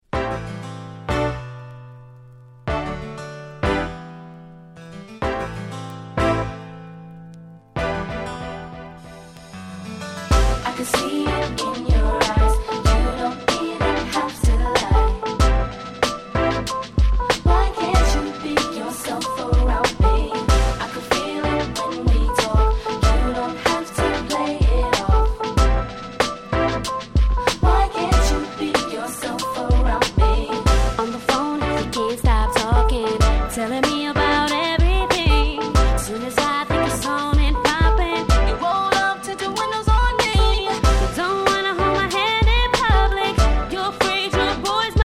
まさにストリートヒット！！！